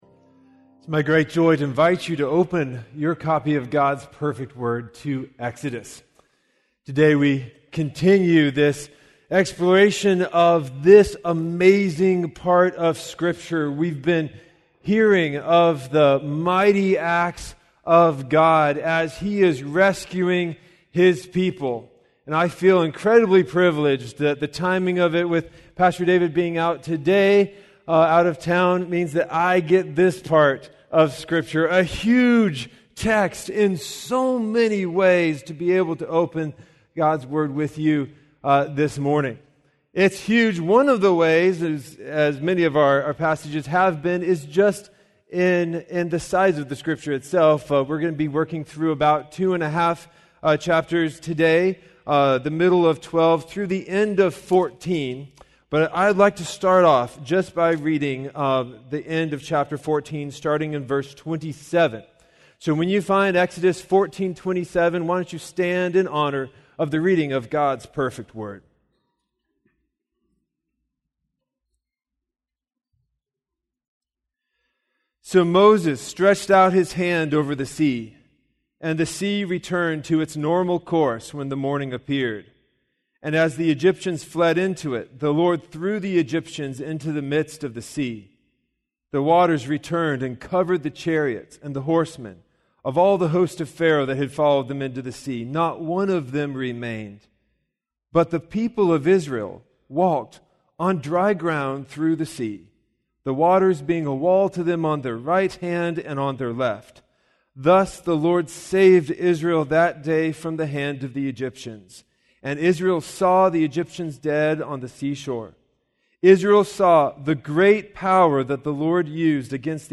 In this sermon from our Exodus series we see God's faithfulness, power, and nearness displayed through his sure promises.